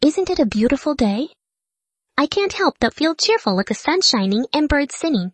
reference_wavs_cheerful.wav